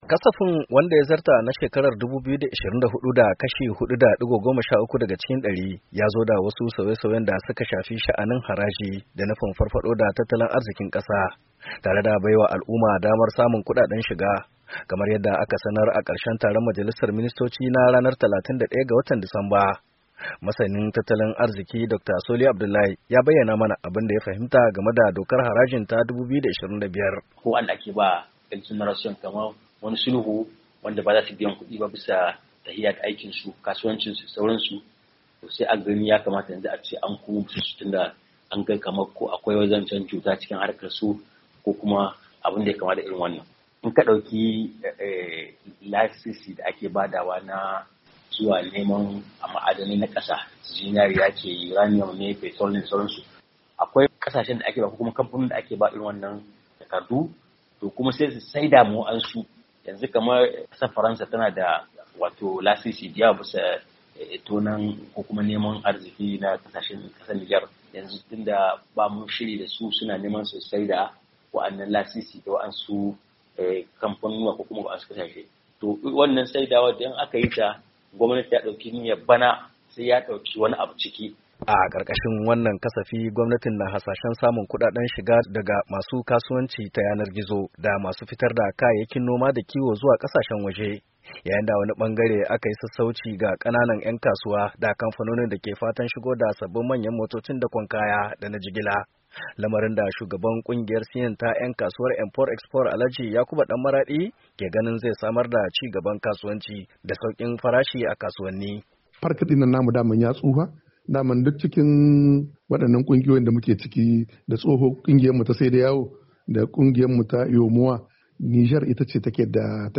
NIAMEY, NIGER —